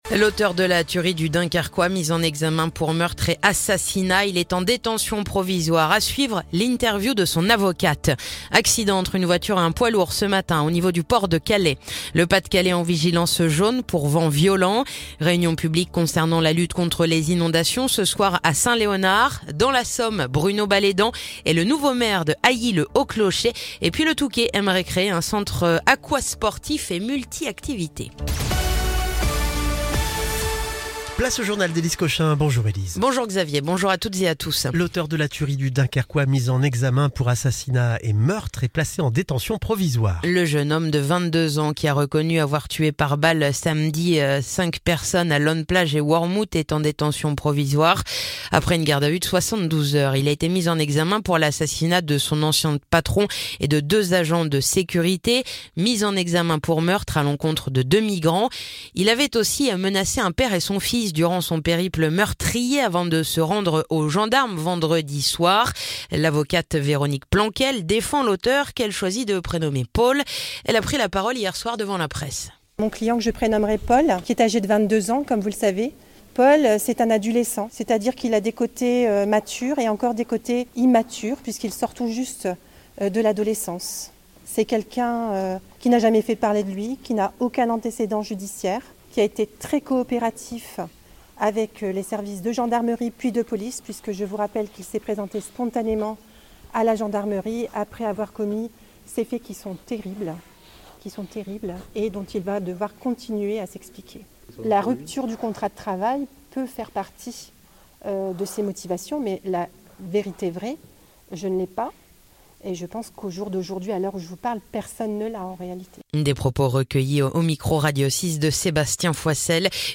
Le journal du mercredi 18 décembre